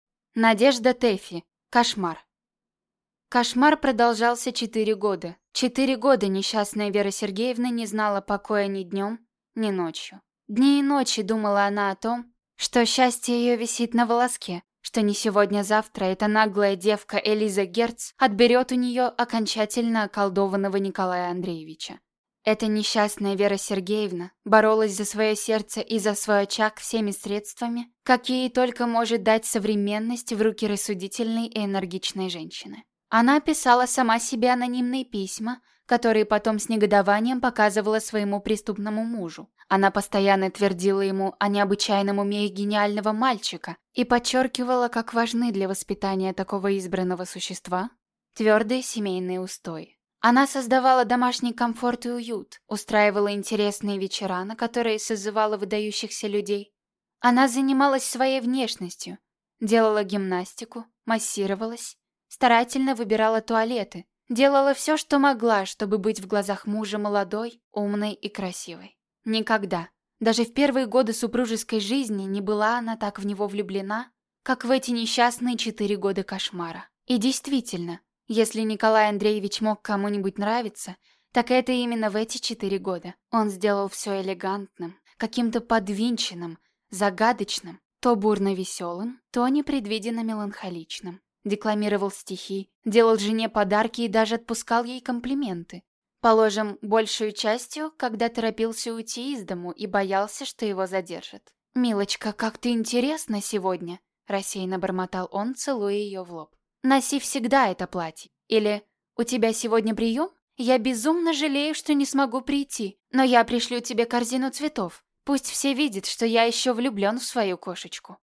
Аудиокнига Кошмар | Библиотека аудиокниг